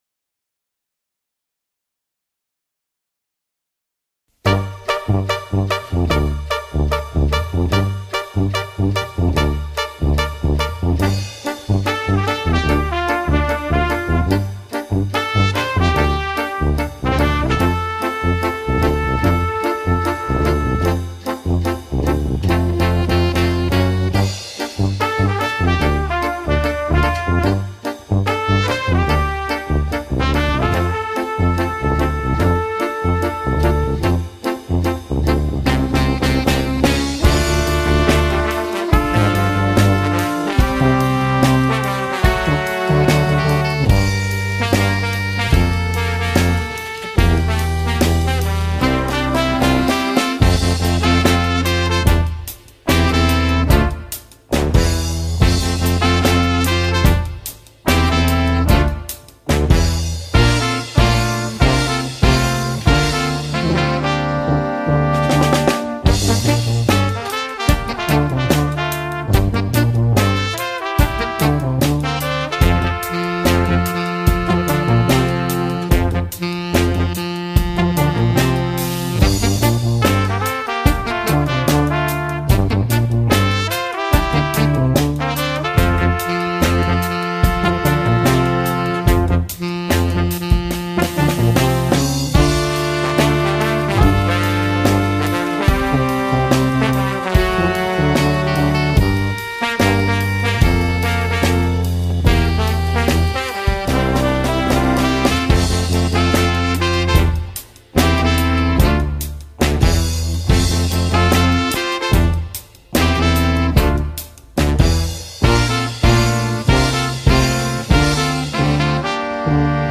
relentless drums, rumbling tuba and wailing horns